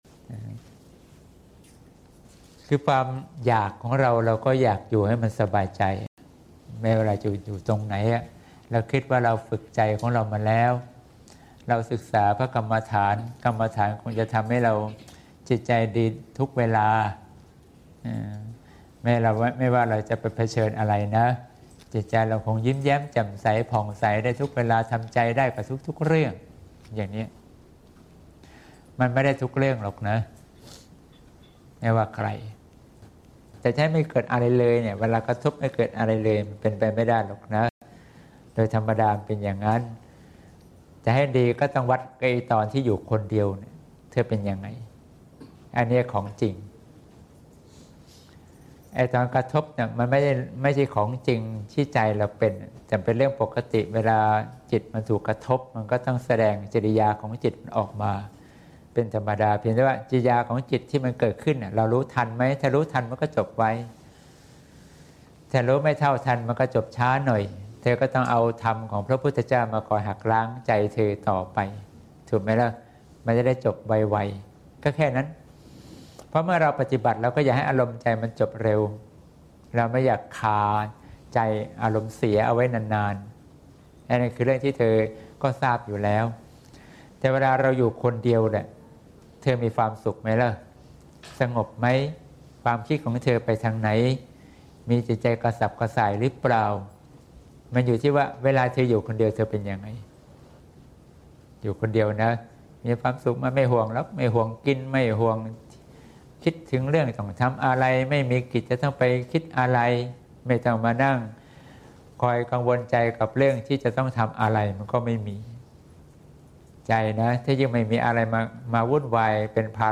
เสียงธรรม (เสียงธรรม ๑๓ ก.ค. ๖๘)